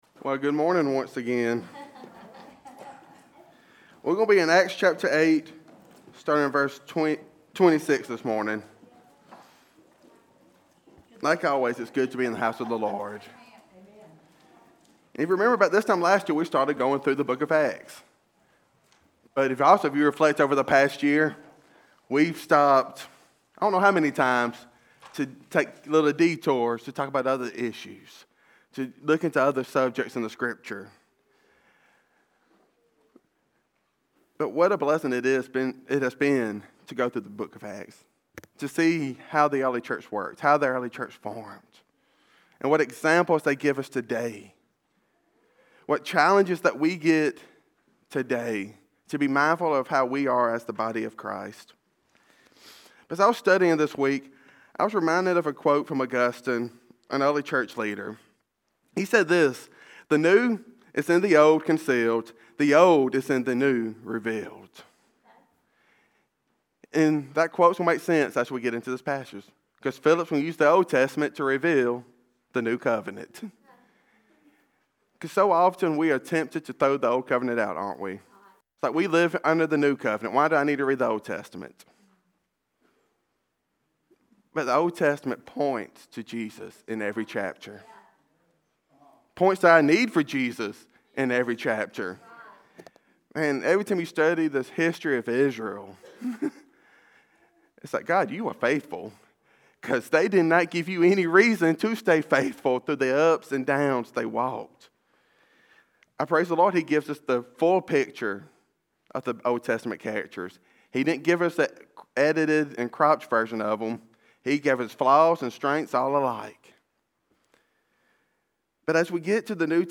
Are you living with spiritual urgency? In this sermon